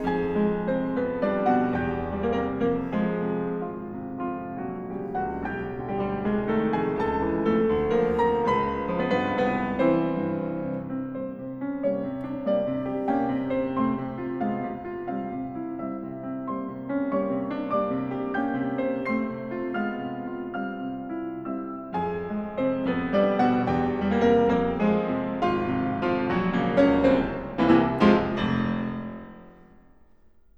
Carnaval’s title and content were partially inspired by Robert Schumann’s Op 9 aka Carnaval: 21 delightful & cryptic solo piano pieces representing masked revellers at, yes, a Carnival.